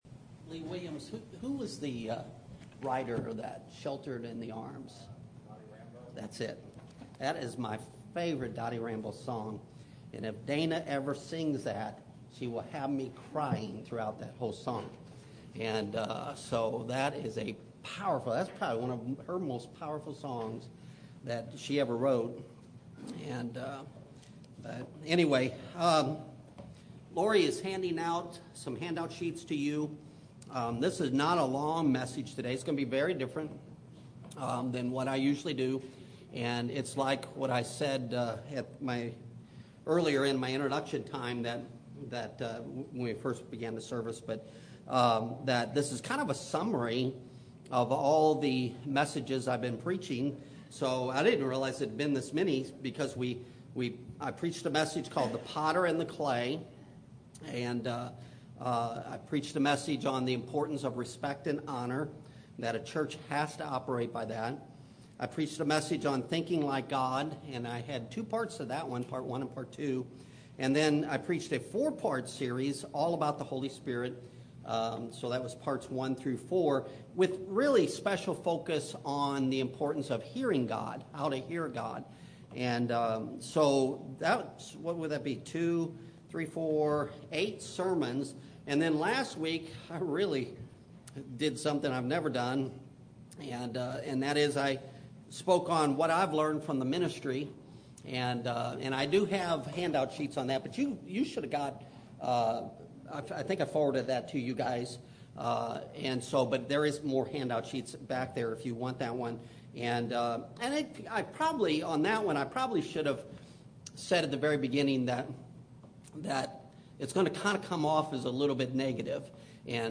Sunday Morning Worship Service – September 1, 2024 First Church of the Nazarene, Republic, MO Speaker